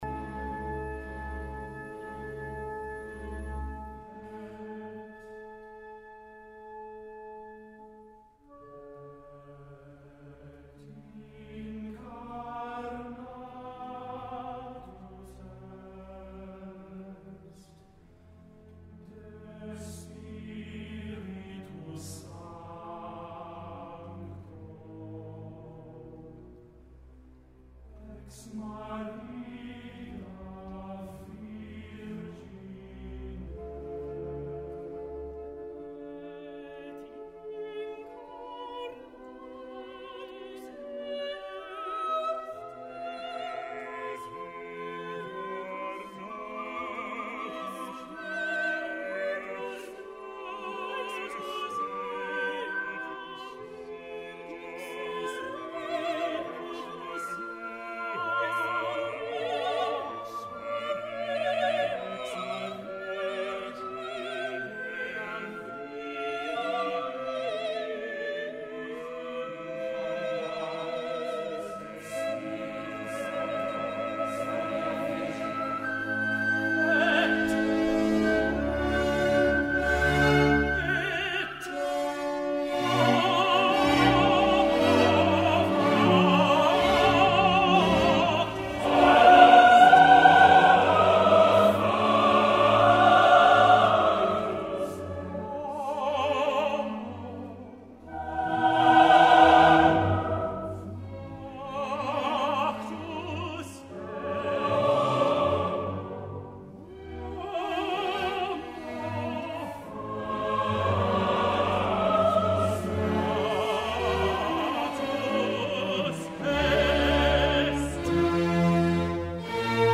Een fragment uit het Credo:
De muziek begint superzacht en sfeervol. Eerst horen we de tekst eenstemmig in het mannenkoor.
Het maakt het tot een lyrisch en liefdevol geheel, lyrisch vooral door de trillers van de fluit.
Heel nadrukkelijk worden alle woorden neergezet, zowel door de tenor als door het volledige koor.
“Sub Pontio Pilato” wordt zeer nadrukkelijk eenstemmig gezongen: de opdracht tot kruisigen klinkt als een rechterlijk bevel, gegeven door Pontius Pilatus.
De tekst vanaf “Sub Pontio Pilato” wordt nog een keer herhaald, nu wordt de ter aarde bestelling op het einde nog meer plastisch uitgebeeld: “et sepultus est” wordt op een toonhoogte bijna gefluisterd.
missa-solemnis-credo.mp3